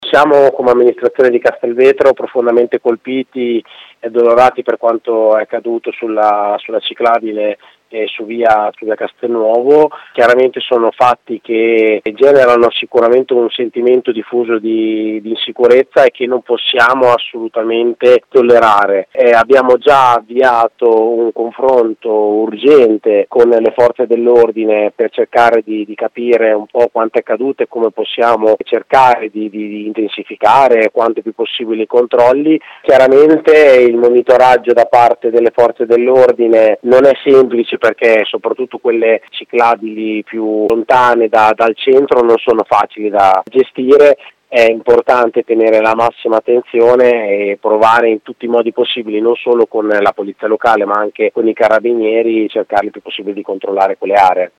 Qui sotto il commento del sindaco di Castelvetro Federico Poppi: